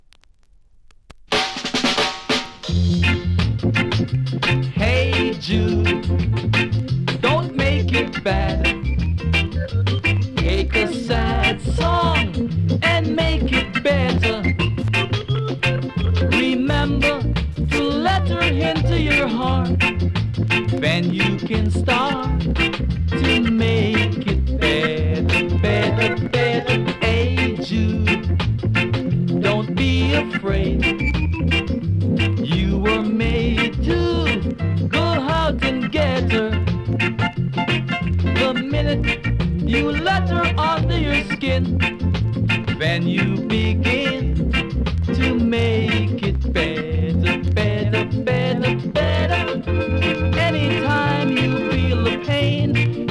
7inch
両面盤の見た目は悪いですが音は良好です。